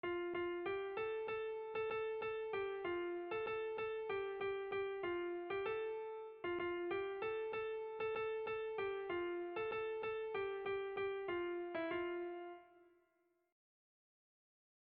Bertso melodies - View details   To know more about this section
Erromantzea
A1A2